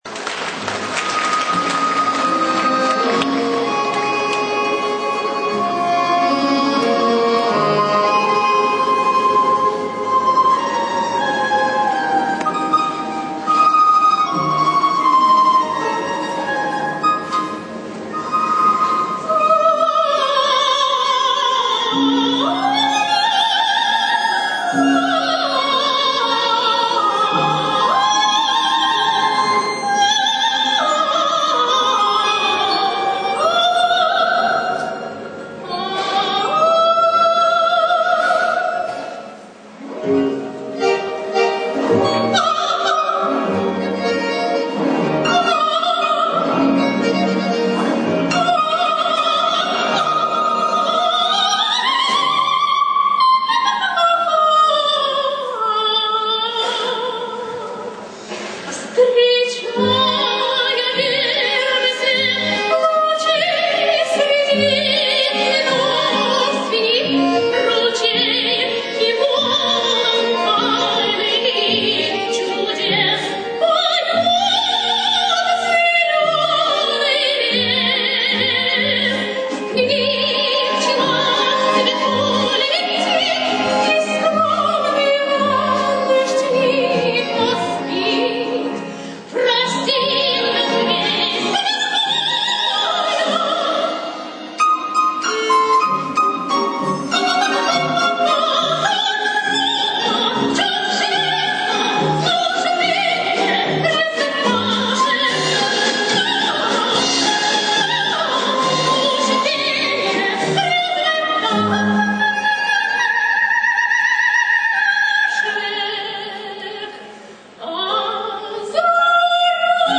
Архив концертных выступлений
Ансамбль солистов оркестра "Россияне"